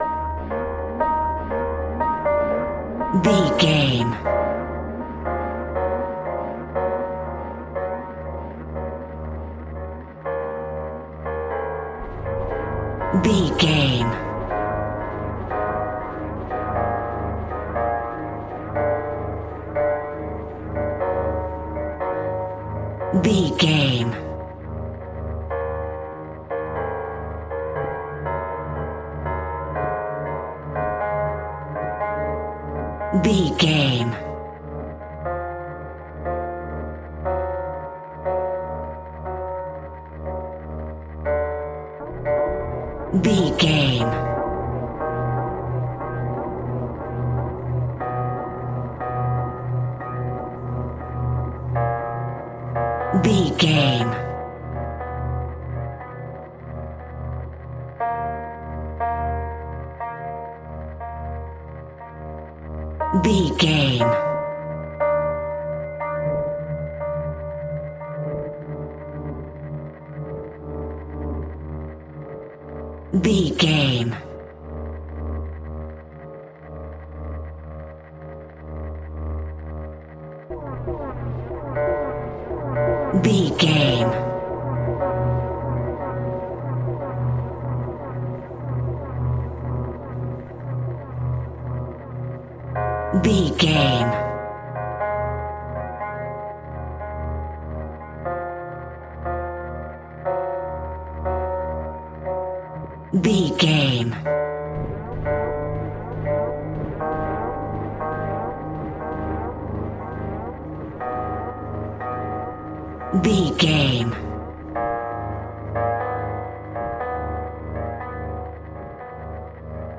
Thriller
Aeolian/Minor
E♭
Slow
scary
tension
ominous
dark
suspense
haunting
eerie
ethereal
synthesiser
electric piano
keyboards
ambience
pads
eletronic